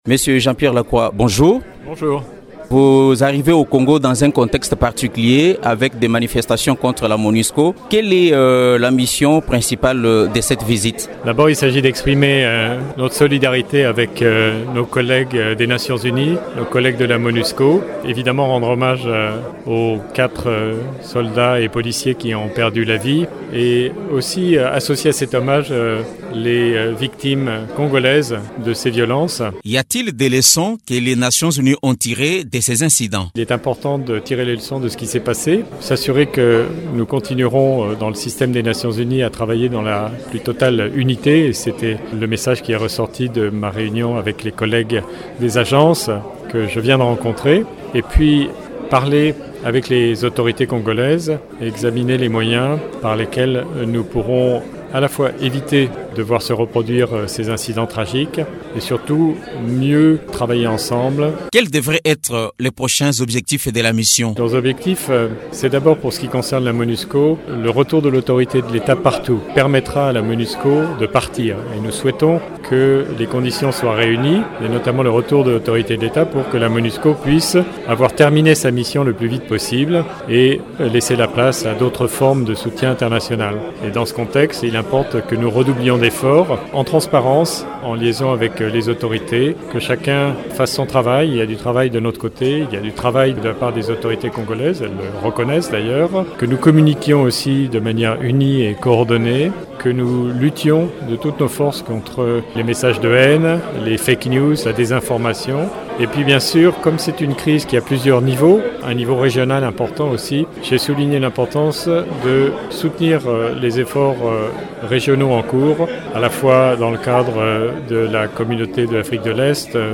Invité de Radio Okapi, ce haut fonctionnaire de l’ONU rend hommage à tous ces qui ont perdu la vie lors des affrontements anti-MONUSCO dans les grandes villes de la partie Est du pays.